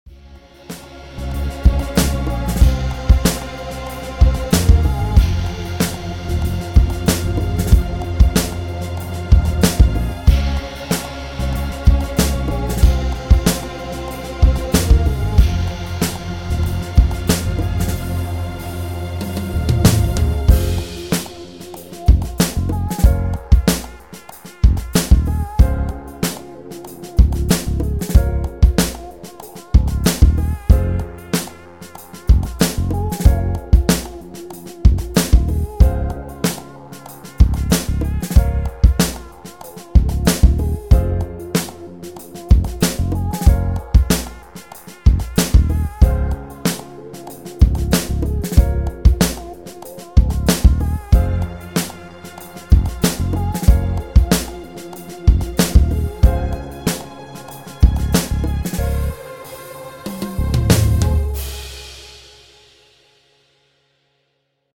beaty